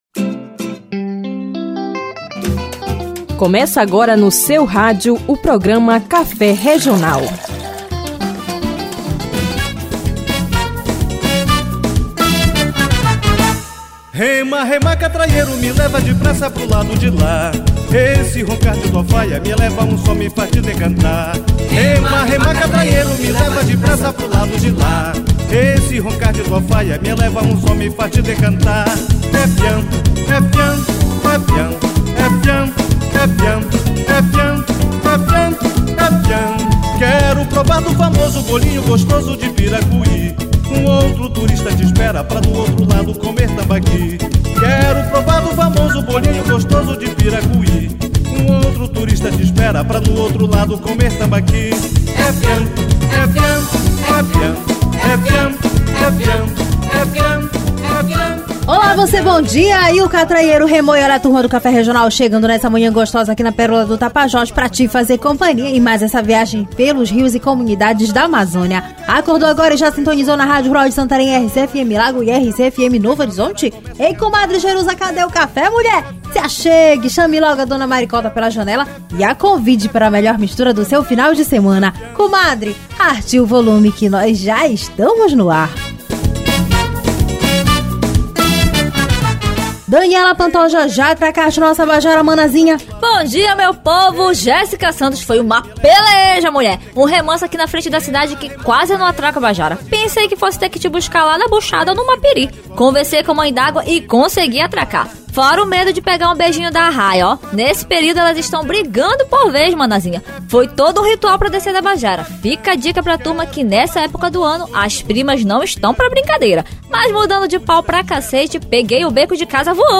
A programação inclui música, informação, agenda de ações da sociedade civil, entrevistas e outros conteúdos.